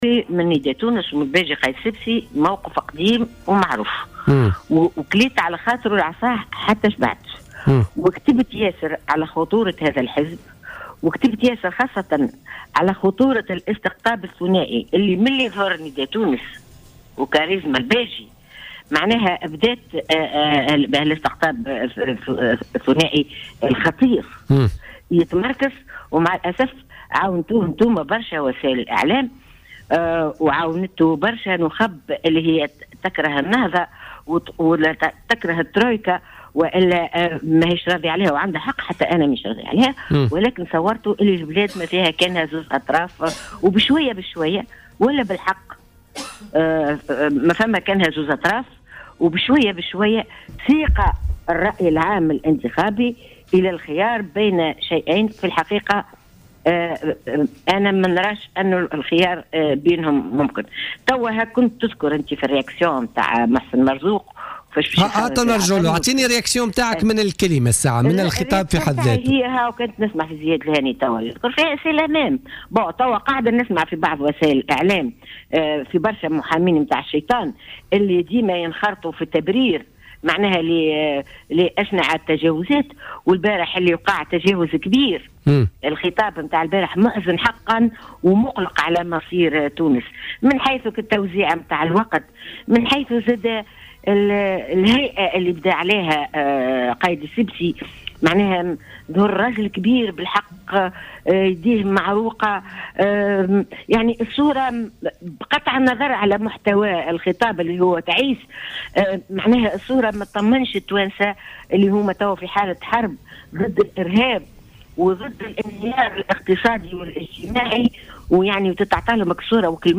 وأضافت في اتصال هاتفي بـ "الجوهرة أف أم" في برنامج "بوليتيكا" انه من الواضح أن الباجي قايد السبسي انحاز لابنه في شأن حزبي وأيضا لمنظمة الأعراف.